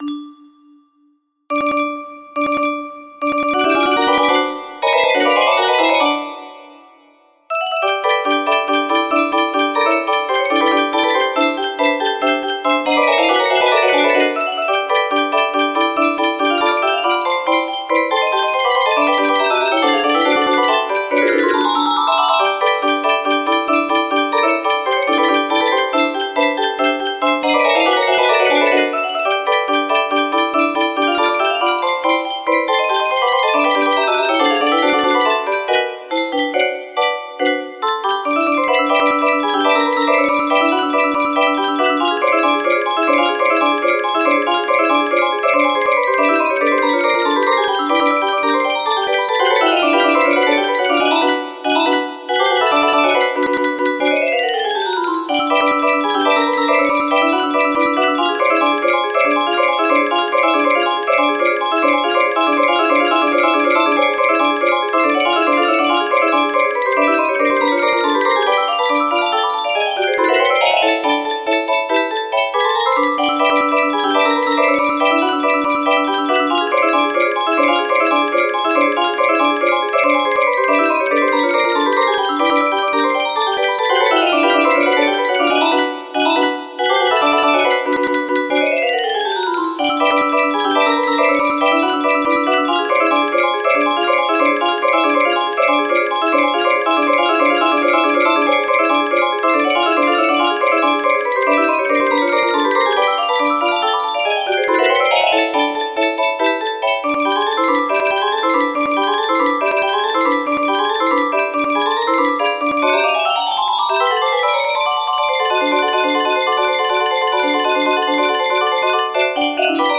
Circus Galop Test music box melody